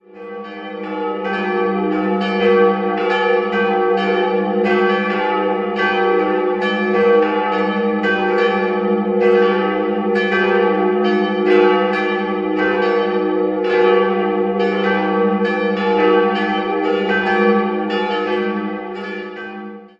St. Jakob wird heute als Seminarkirche des Regensburger Priesterseminars genutzt. 3-stimmiges Geläut: g'-a'-d'' Die große Glocke wurde in der zweiten Hälfte des 13. Jahrhunderts gegossen, die mittlere im Jahr 1450 und die kleine stammt von Martin Neumair (Stadtamhof) von 1722.